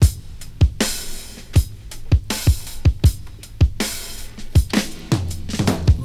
• 79 Bpm Breakbeat E Key.wav
Free drum loop sample - kick tuned to the E note. Loudest frequency: 1558Hz
79-bpm-breakbeat-e-key-xz4.wav